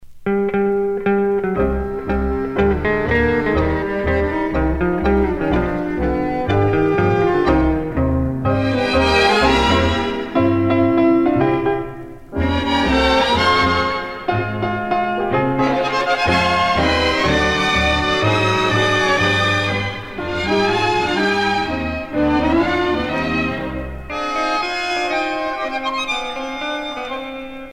danse : tango